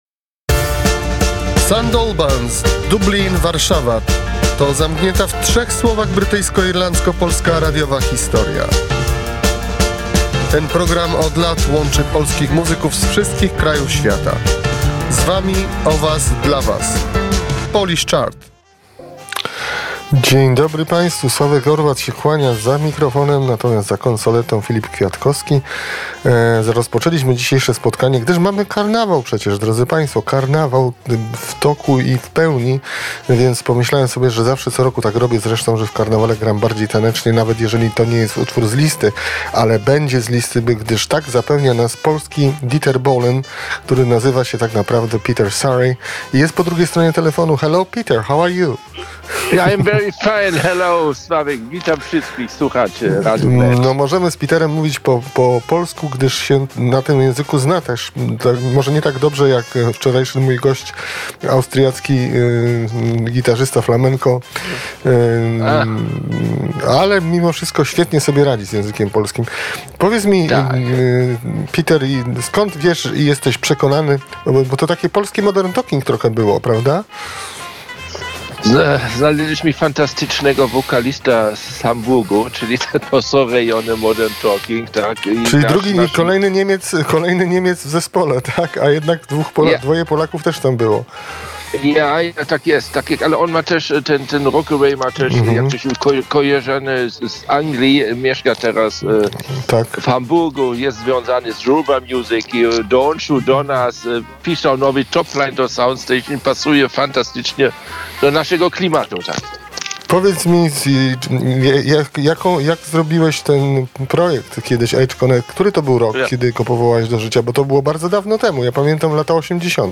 POLISH CHART - Wywiad z zespołem